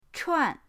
chuan4.mp3